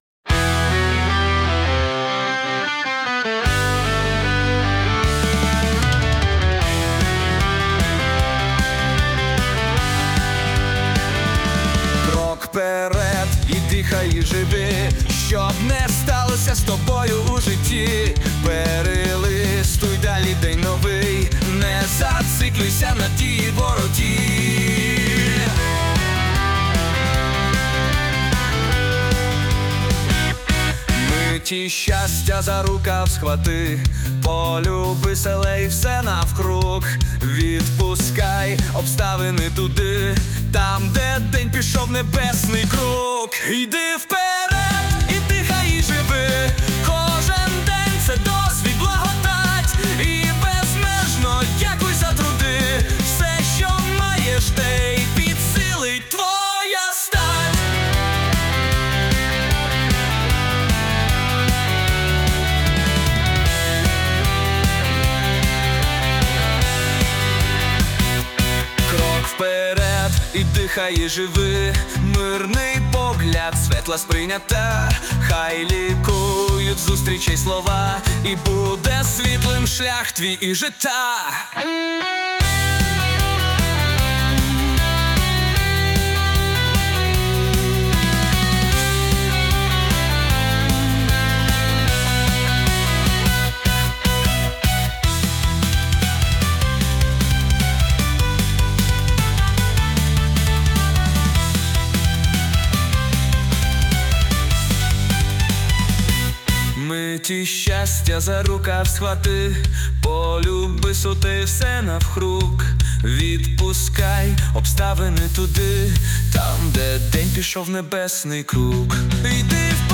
ПІСНЯ ♡ КРОК ВПЕРЕД ♡